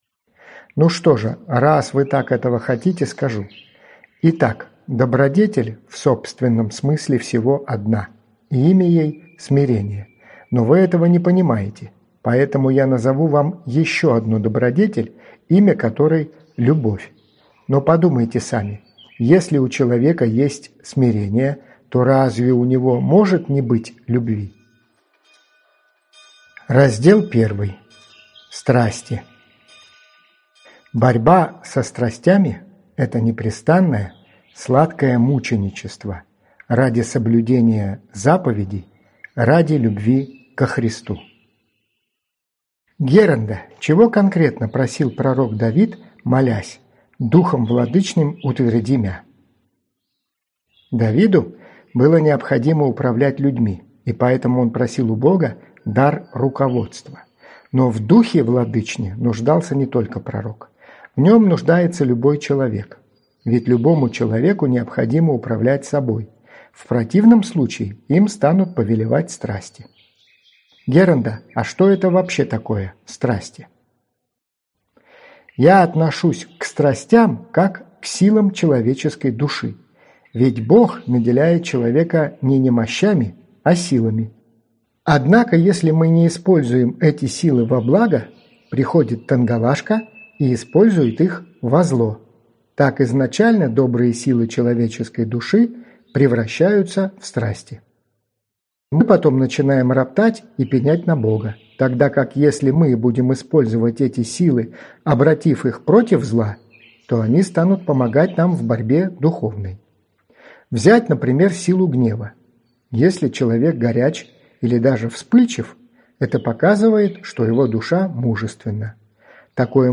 Аудиокнига Слова. Том V. Страсти и добродетели | Библиотека аудиокниг
Прослушать и бесплатно скачать фрагмент аудиокниги